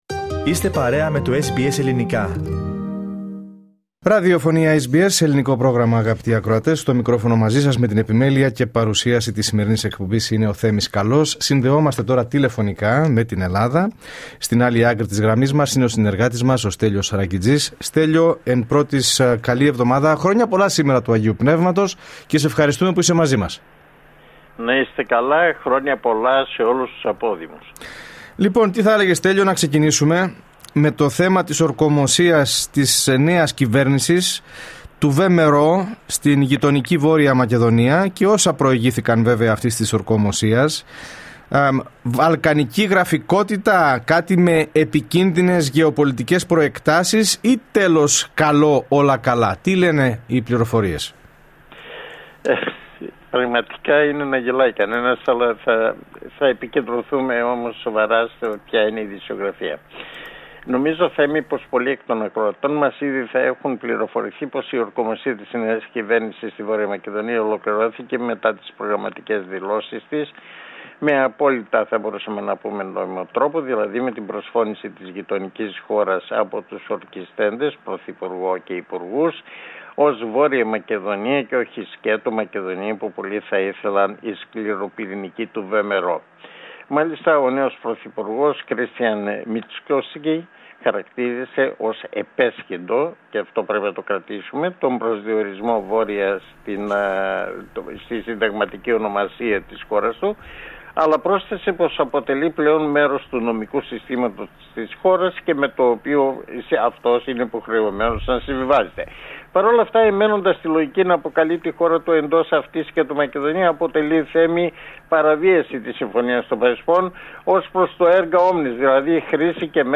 Ακούστε την εβδομαδιαία ανταπόκριση από την Ελλάδα